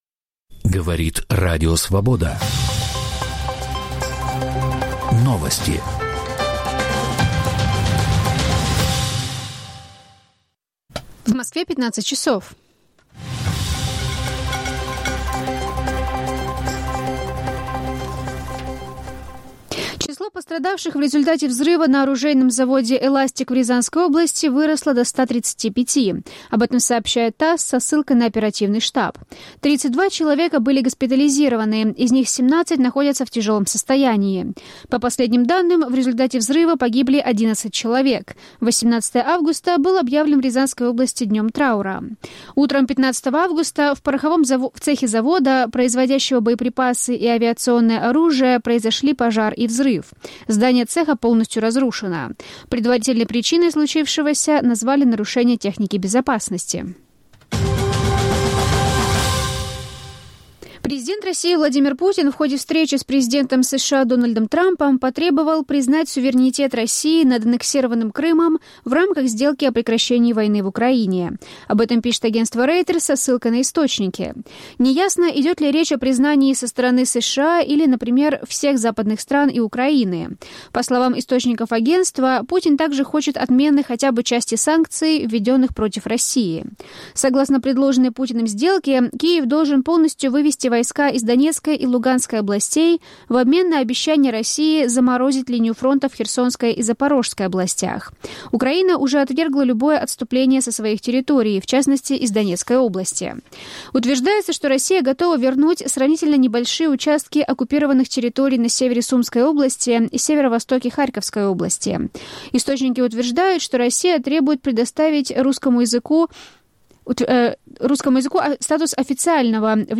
Аудионовости